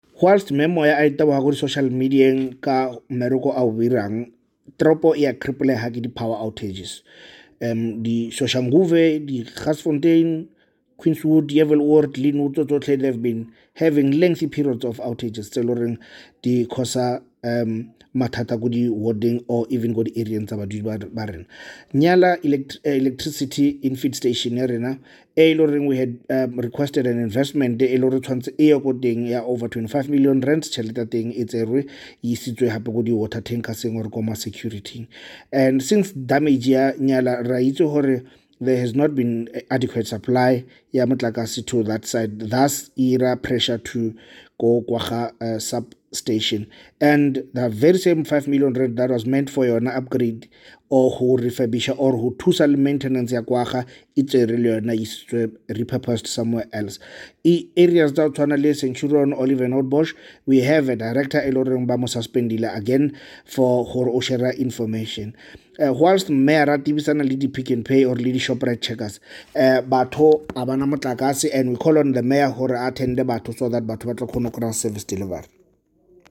Note to Editors: Please find English and Tswana soundbites by Cllr Themba Fossi